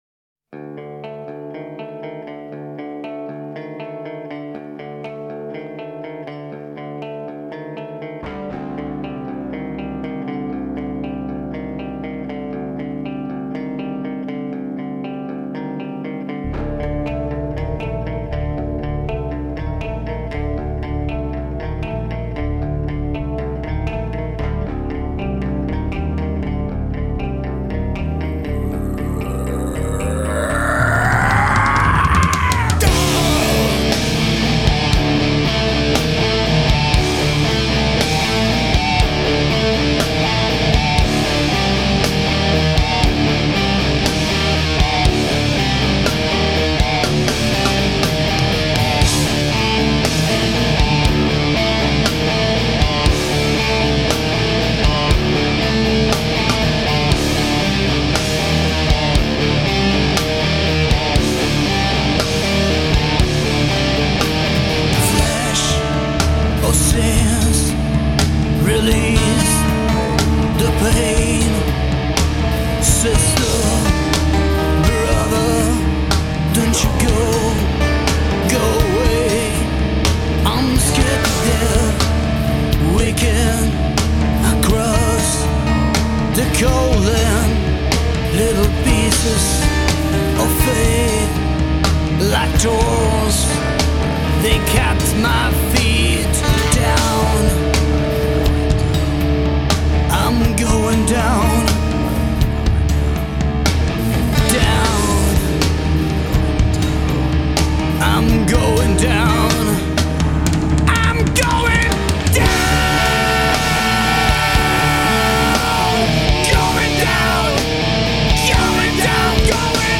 Nie ma tu motania w technice i wirtuozerskich popisów.